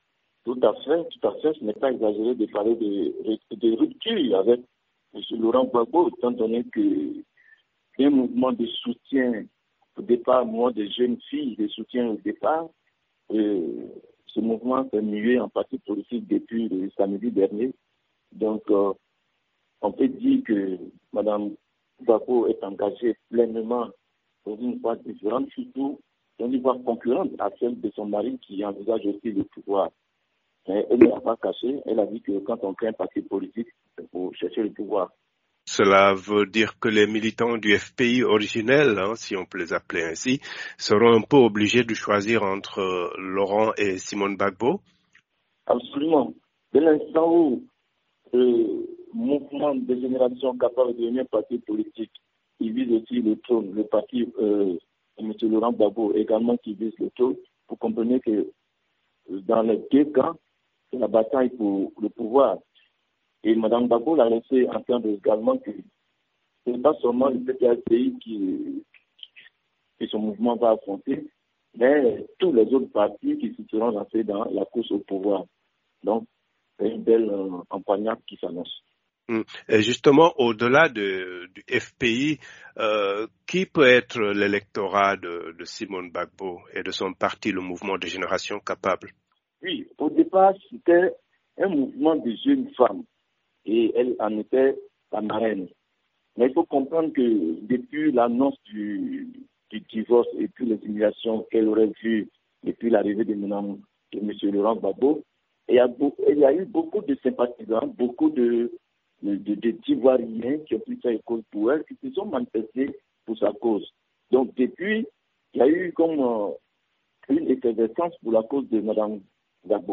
Est-ce la fin du compagnonnage politique de qui a été un couple emblématique dans la vie politique ivoirienne ? Analyse du journaliste et analyste politique